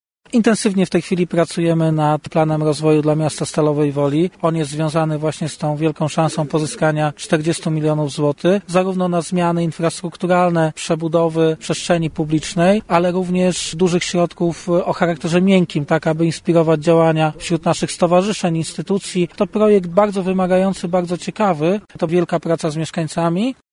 Dlatego tak ważne jest włączenie mieszkańców w jego tworzenie i konsultacje w różnych dziedzinach. Mówił o tym prezydent Stalowej Woli Lucjusz Nadbereżny: